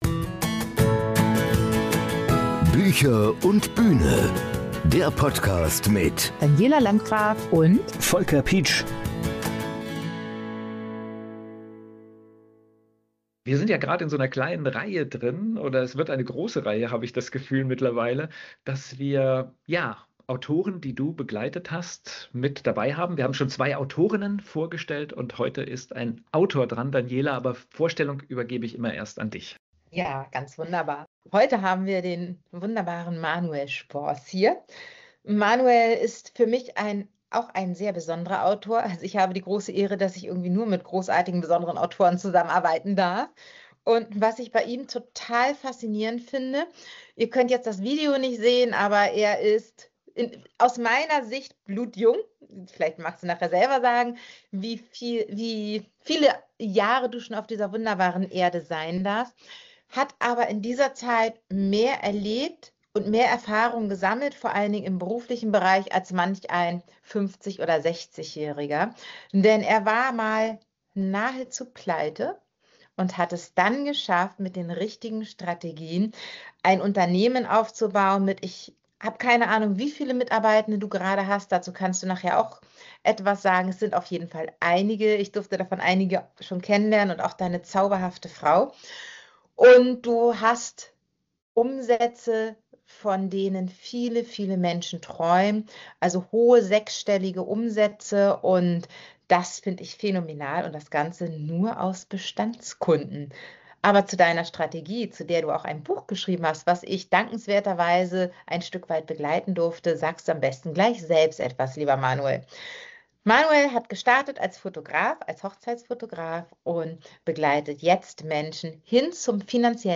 In dieser Episode unserer Autoren-Reihe sprechen wir mit einem ganz besonderen Gast: